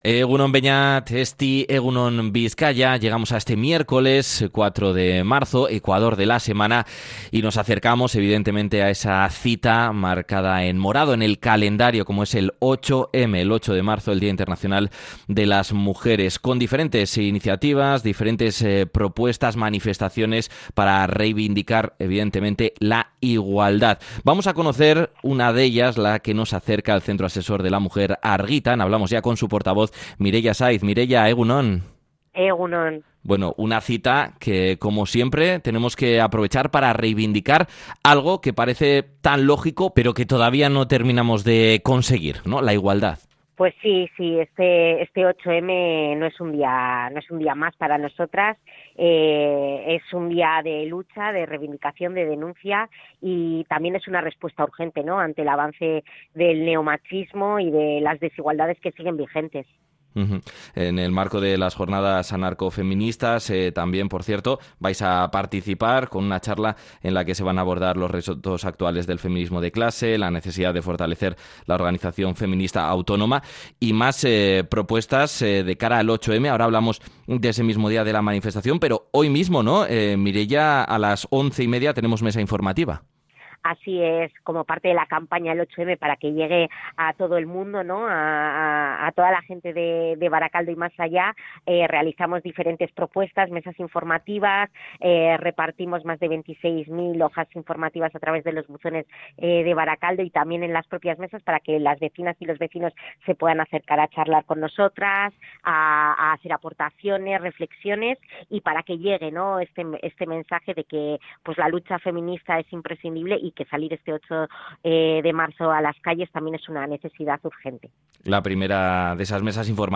ha pasado por los micrófonos de Radio Popular-Herri Irratia para detallar las iniciativas organizadas con motivo del 8 de marzo y advierte del “avance del neomachismo” como uno de los principales retos actuales del movimiento feminista.